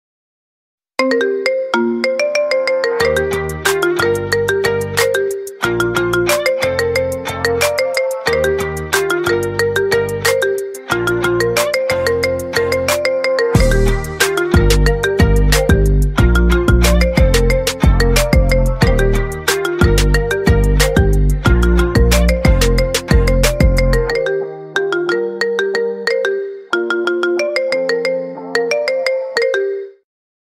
Kategorie Marimba Remix